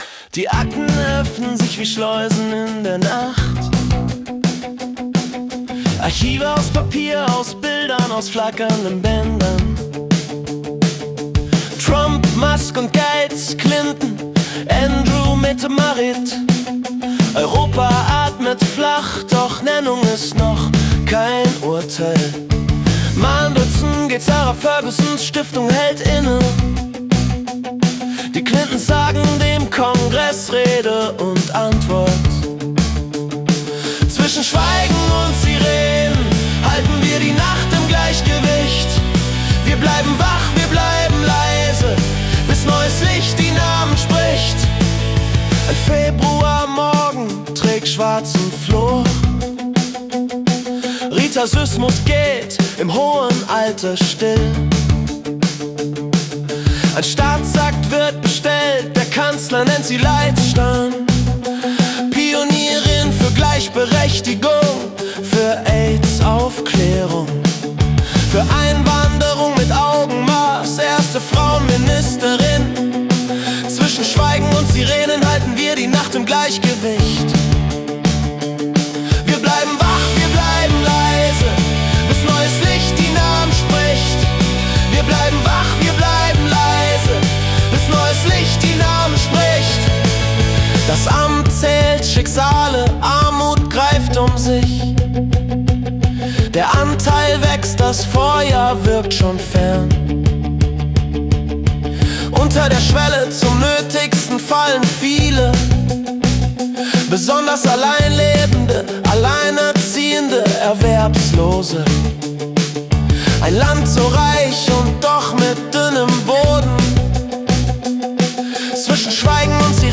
Februar 2026 als Singer-Songwriter-Song interpretiert.